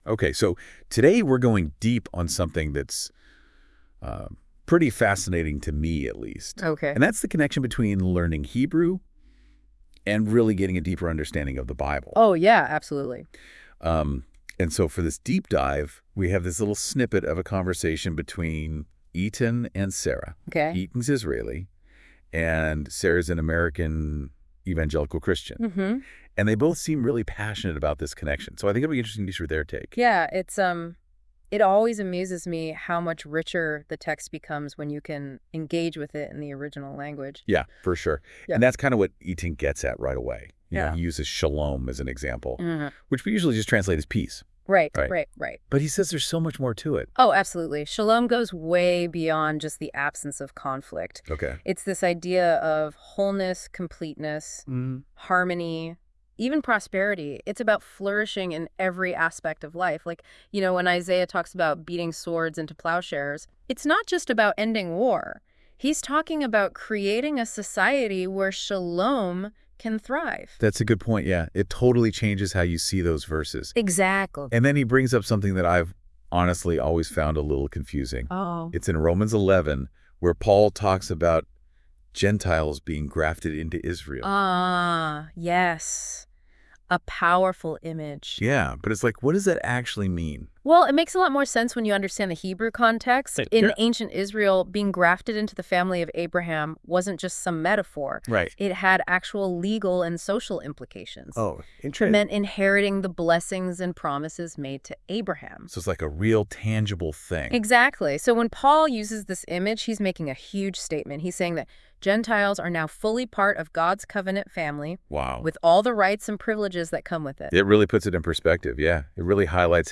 A discussion exploring how learning Hebrew enriches one’s understanding of the Bible.
The conversation highlights the interconnectedness of language, faith, and scriptural interpretation. Both characters emphasize the importance of careful study and insightful understanding of God’s word.